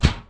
shield-hit-5.ogg